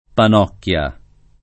panocchia [ pan 0 kk L a ]